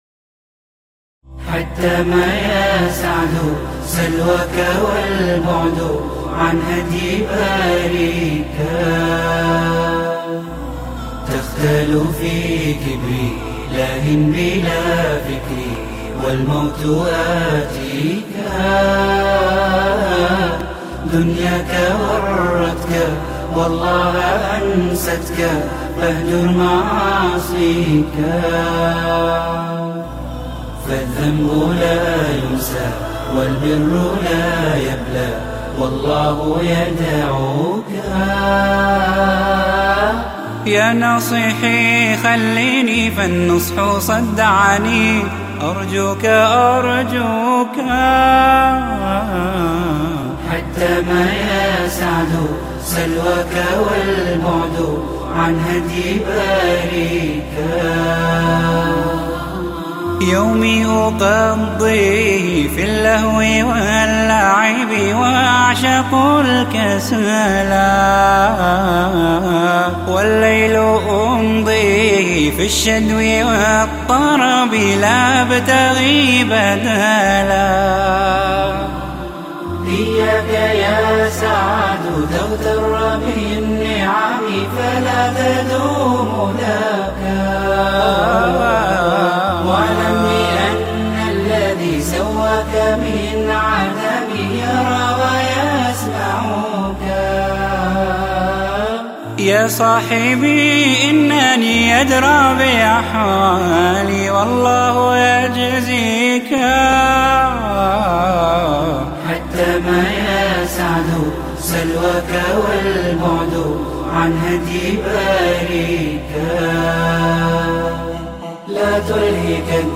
This beautiful nasheed explores the profound Islamic teachings about the afterlife, urging the listener to reflect on their behavior and distance from the guidance of the Creator before it is too late. It is a wake-up call to abandon sin and pursue righteousness while there is still time.